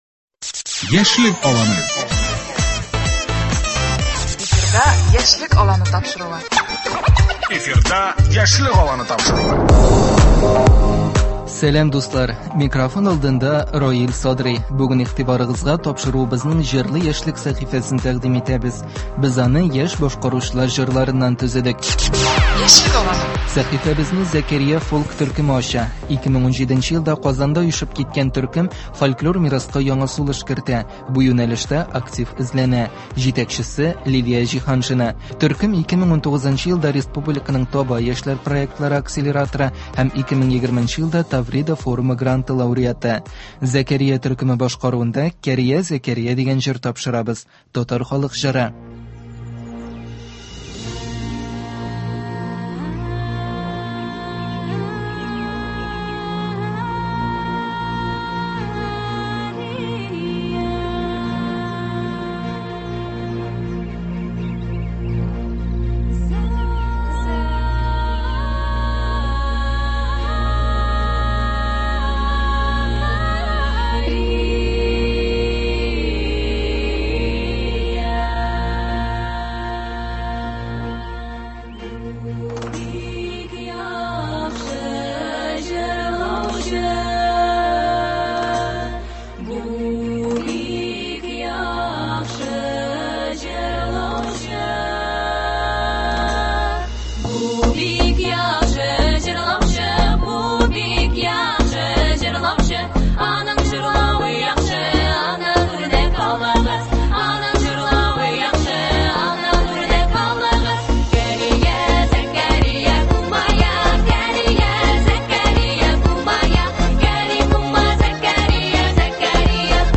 Яшь башкаручылар чыгышы.